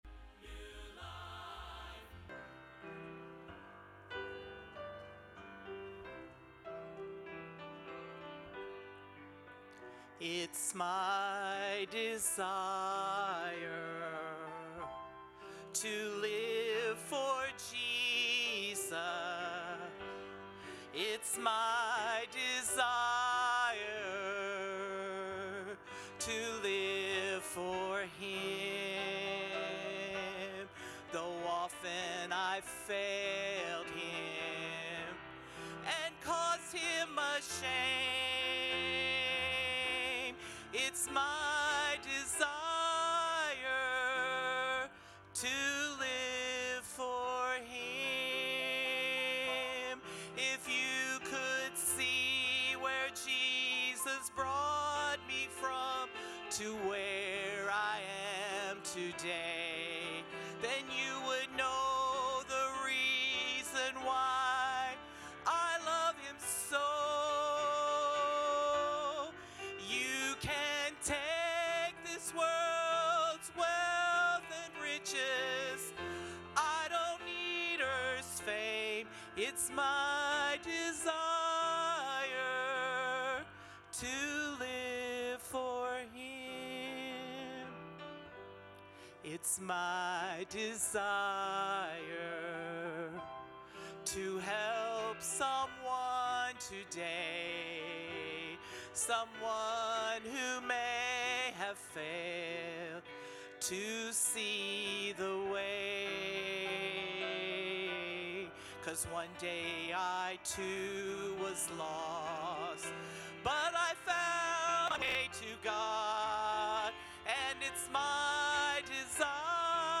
Preaching from the Pulpit | First Baptist Church